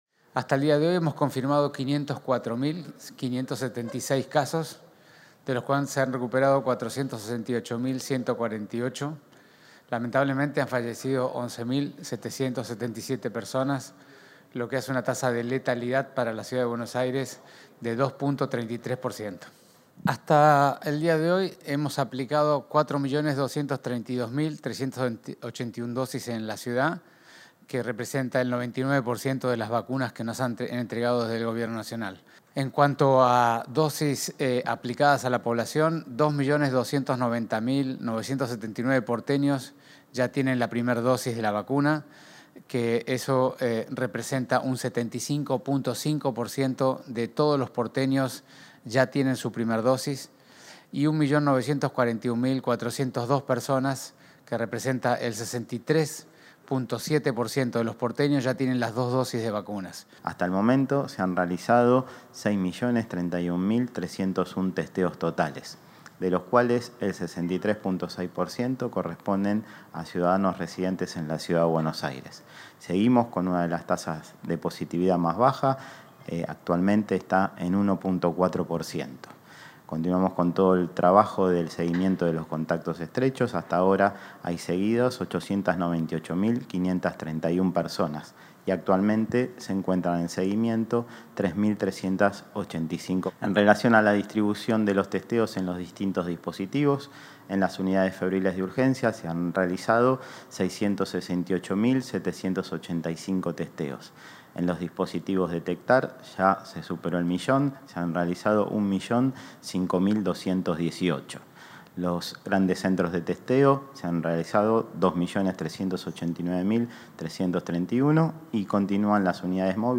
Participaron el ministro Fernán Quirós, el subsecretario de Atención Primaria, Gabriel Battistella, y la directora general de Planificación Operativa, Paula Zingoni.